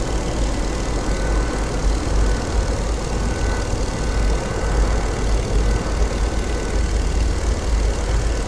plane_helicopter_loop.ogg